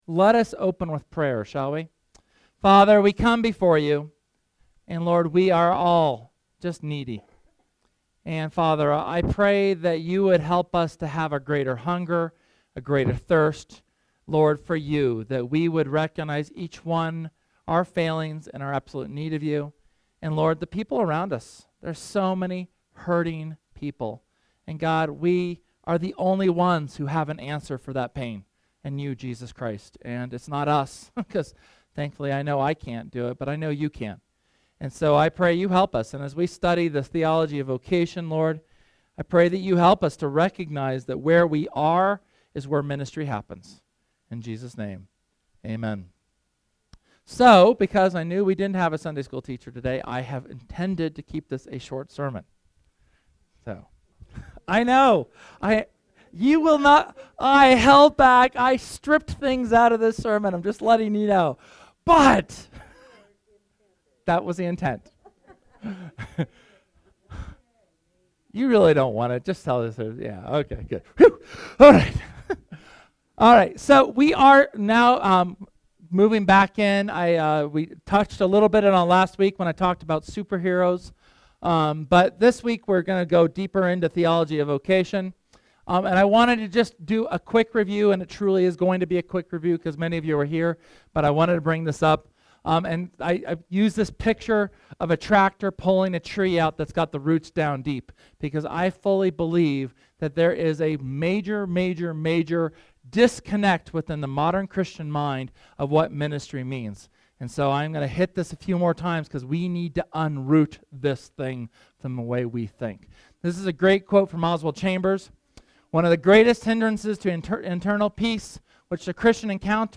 This is the third sermon where we specifically look deeper at our role of Kingdom builders in our work.